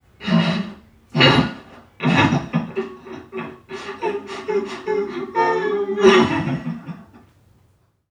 NPC_Creatures_Vocalisations_Robothead [58].wav